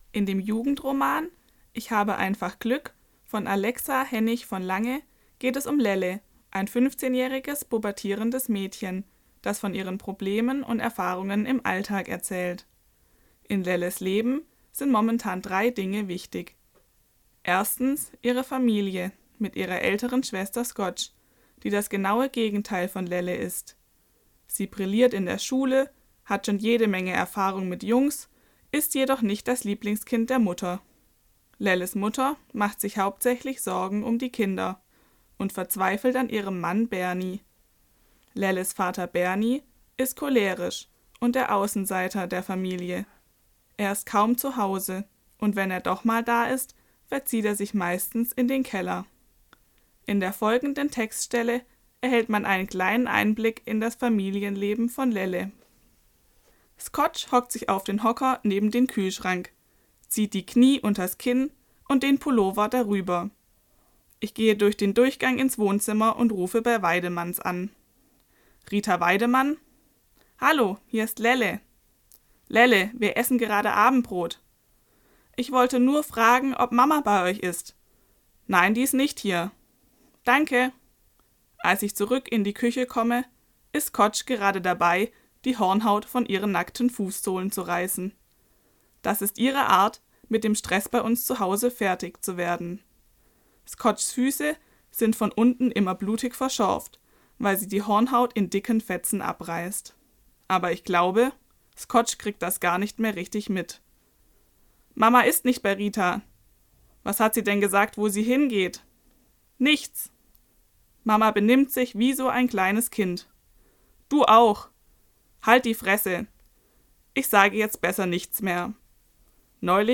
„Ich habe einfach Glück“ von Alexa Hennig von Lange – Buchvorstellung – Podcastarchiv der PH-Freiburg